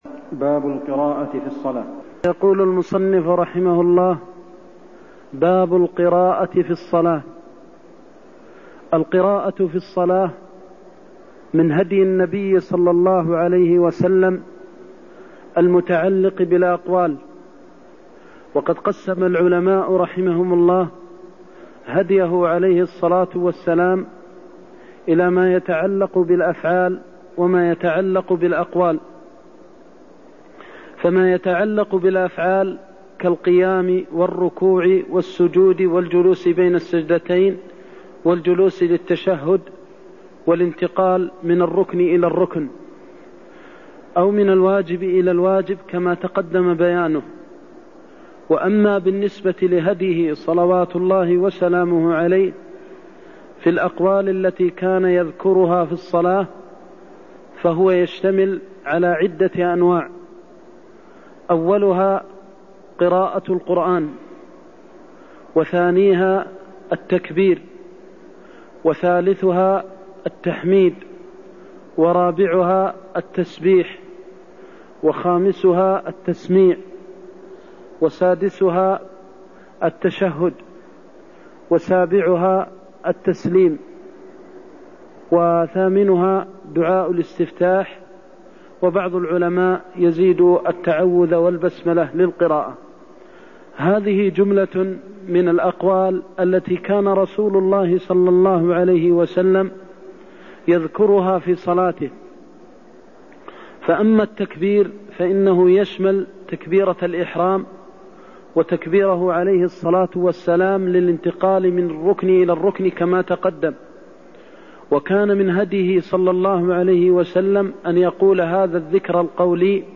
المكان: المسجد النبوي الشيخ: فضيلة الشيخ د. محمد بن محمد المختار فضيلة الشيخ د. محمد بن محمد المختار لا صلاة لمن لم يقرأ بفاتحة الكتاب (93)